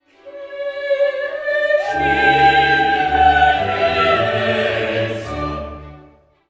“K140” has the most spartan scoring of any of Mozart’s masses, with only violins, basses, and organ continuo.
The Kyrie is a gentle, folklike setting for Type I chorus and soloists in triple time.
Kyrie-Theme-C-1.wav